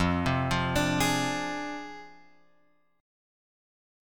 F Minor 6th